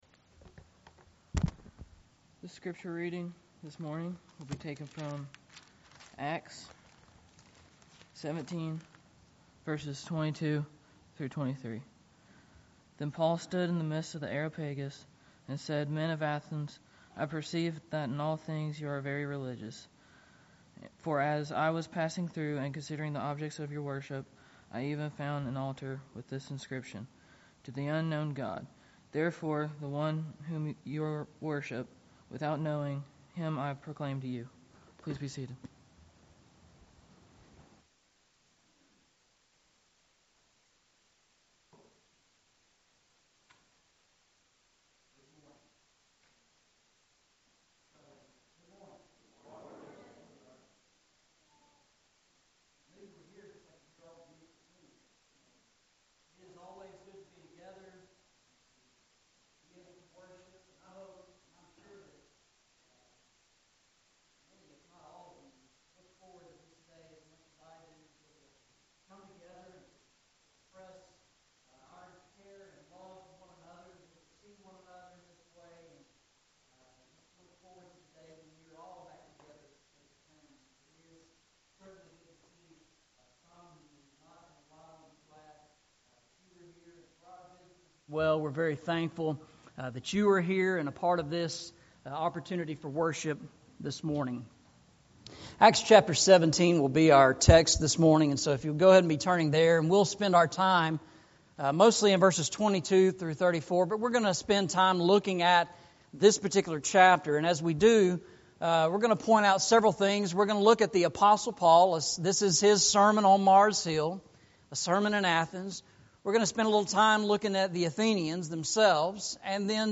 A Sermon in Athens